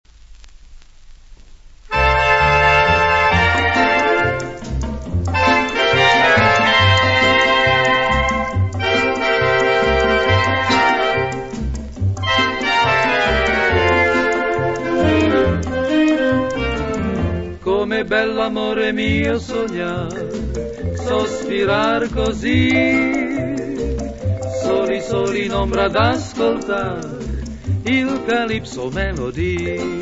Calipso melody